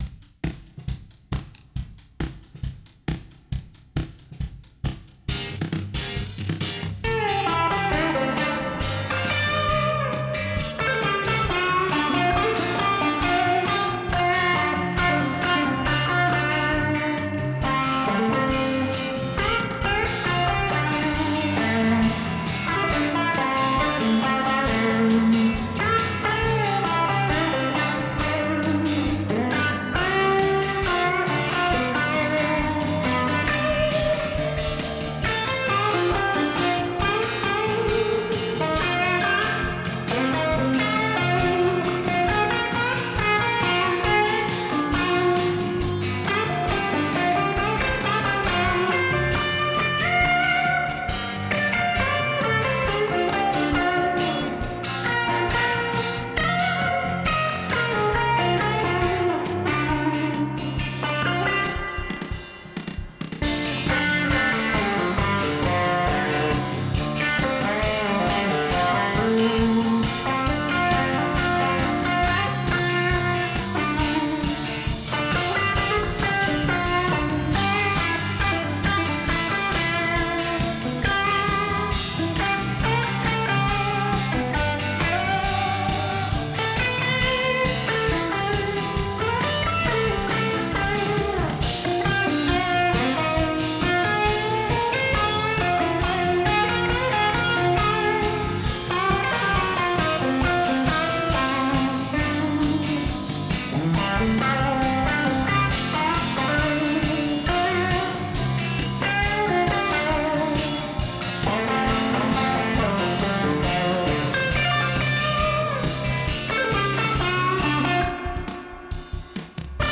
Electric Bass, Drums, Electric Guitars
Genre: Instrumental Rock